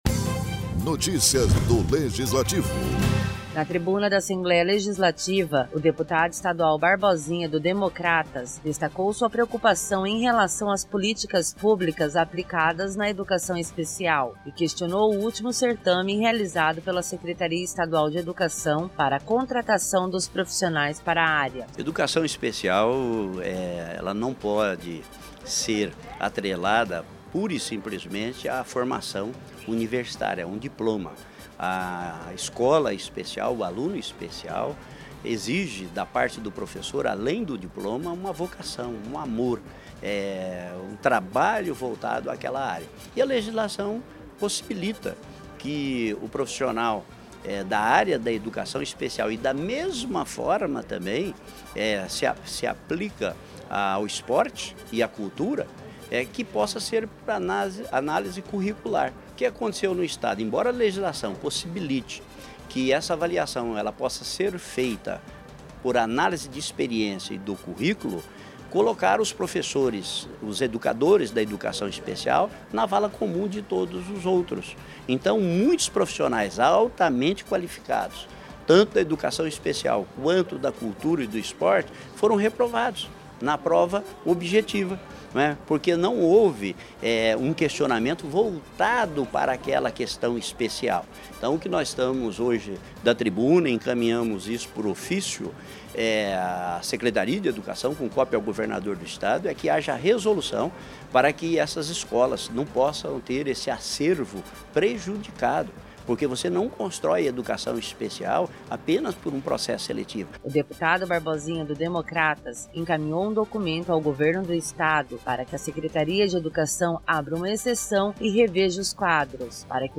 O deputado estadual Barbosinha, do Democratas, usou à tribuna da Assembleia Legislativa para discursar a favor da Educação Especial de Mato Grosso do Sul e questionar o último certame realizado pela Secretaria Estadual de Educação (SED) para contratação de profissionais para a área.